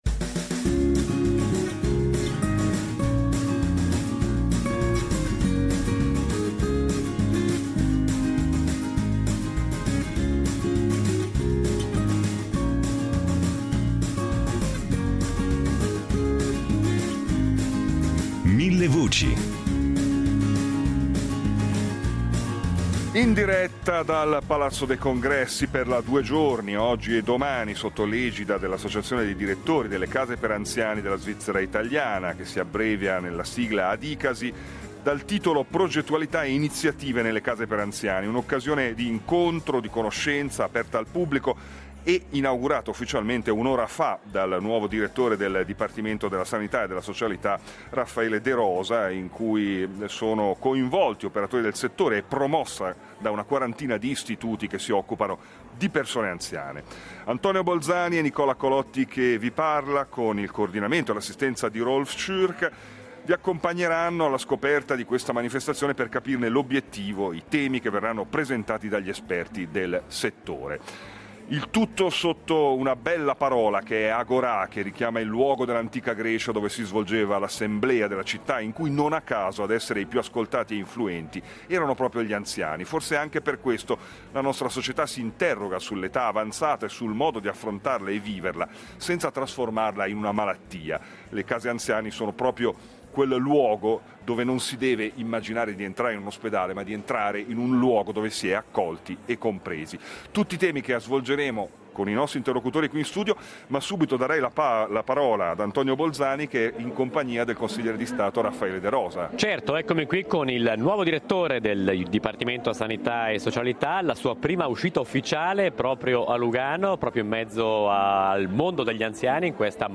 La presenza della nostra radio con una postazione per la trasmissione in diretta sarà un elemento di condivisione aggiuntivo.